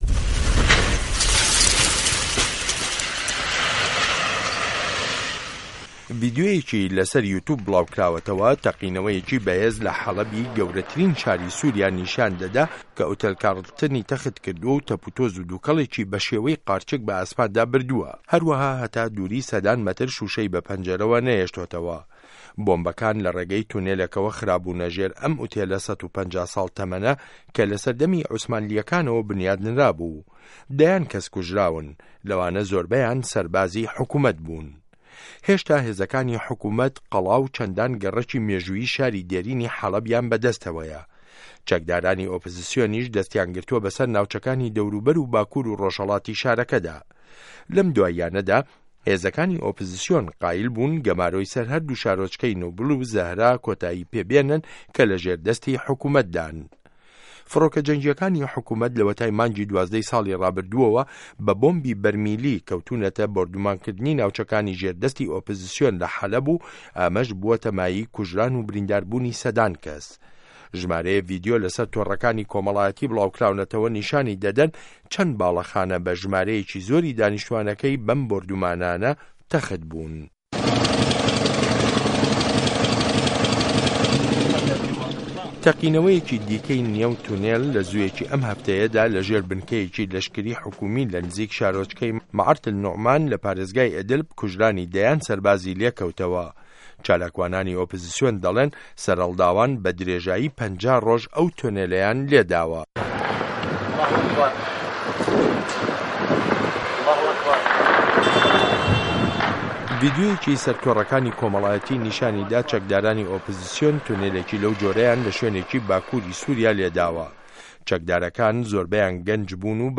ڕاپـۆرتی سوریا